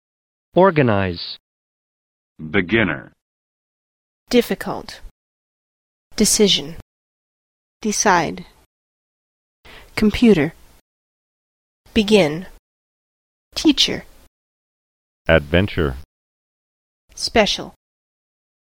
What is the stressed (= strong) syllable? Type a number (1, 2, or 3)
word_stress_02.mp3